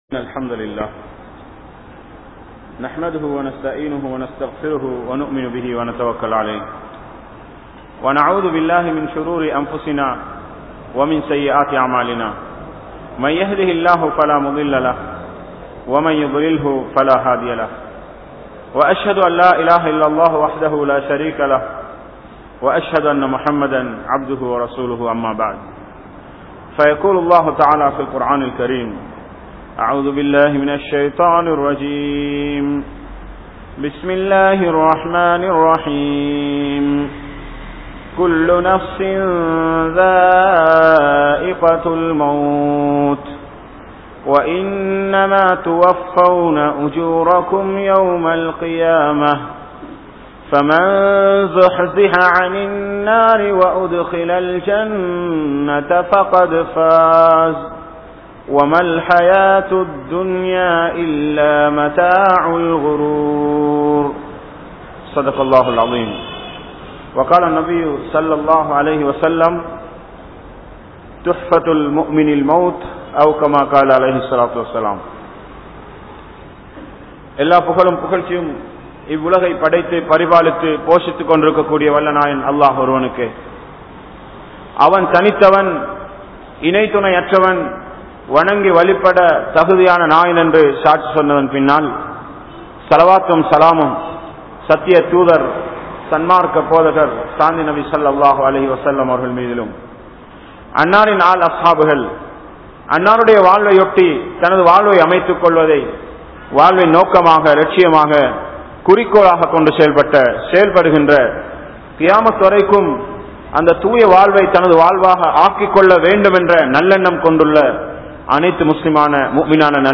The Death | Audio Bayans | All Ceylon Muslim Youth Community | Addalaichenai